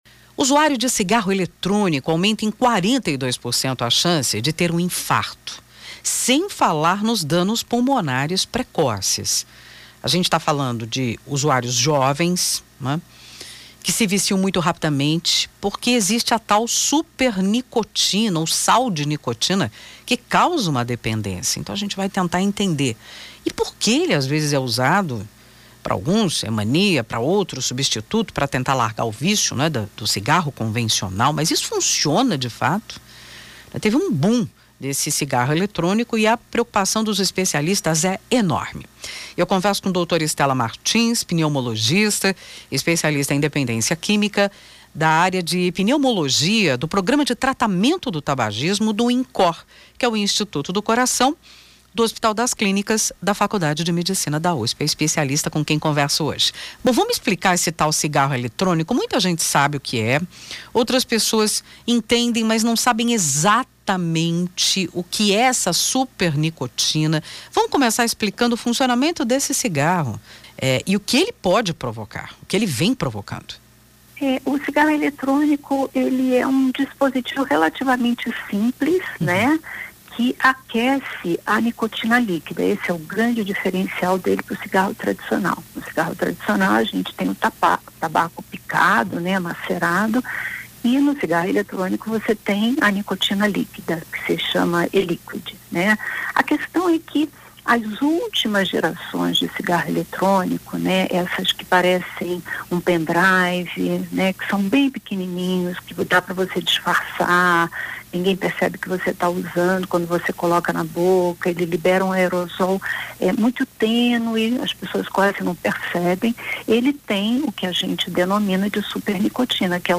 em conversa ao Jornal da USP no Ar 1ª Edição